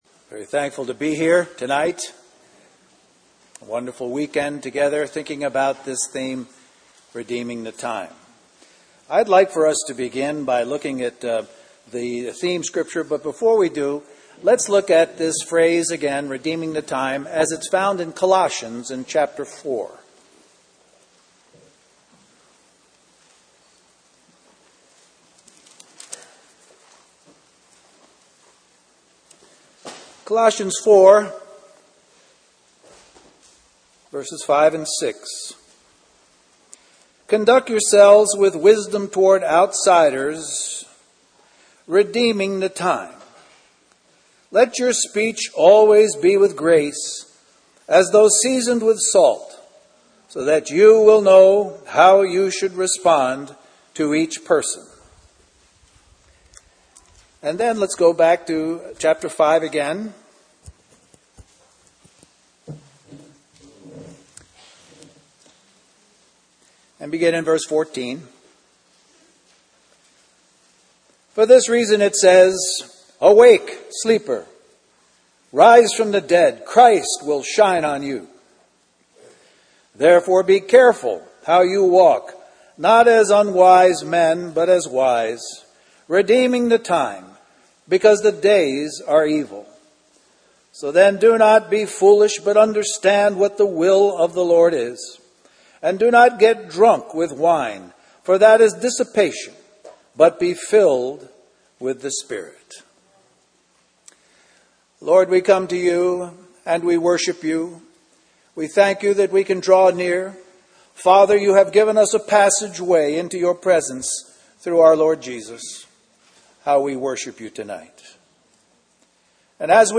A collection of Christ focused messages published by the Christian Testimony Ministry in Richmond, VA.
Harvey Cedars Conference